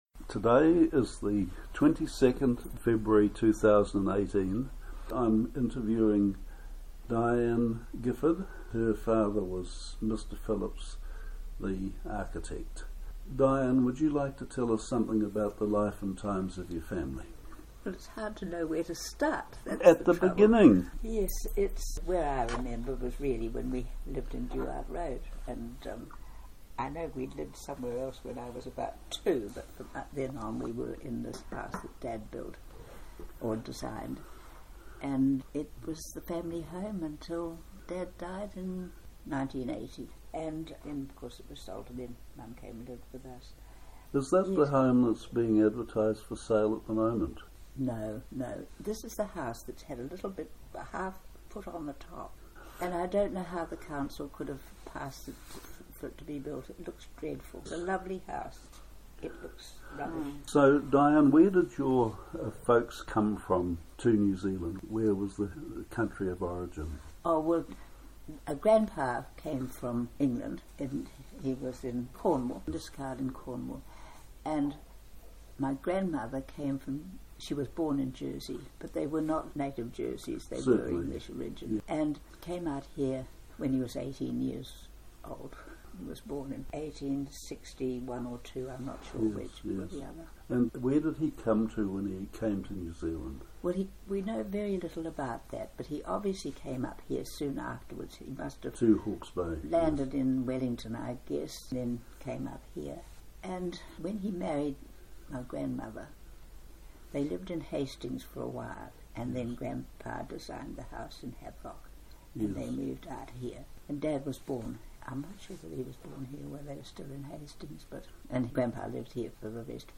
This oral history has been edited in the interests of clarity.